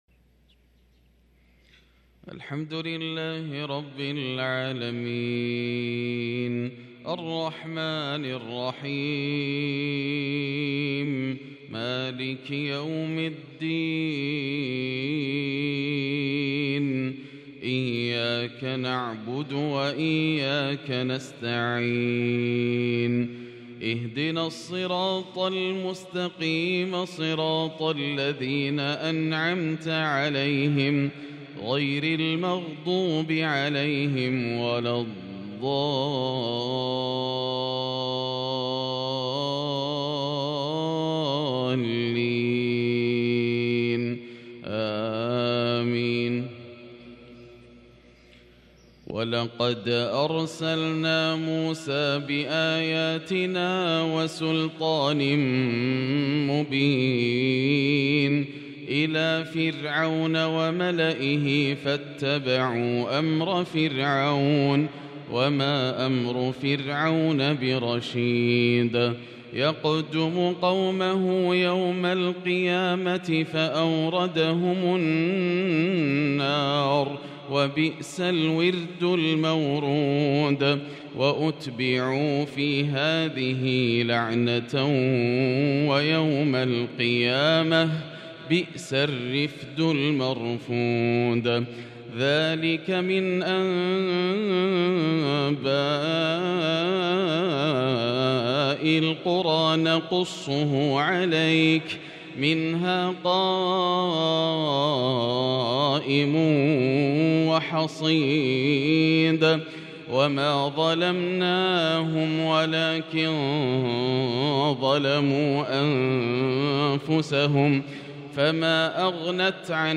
فجر السبت 2-8-1443هـ من سورة هود | Fajr prayer from Surah Hud 5-3-2022 > 1443 🕋 > الفروض - تلاوات الحرمين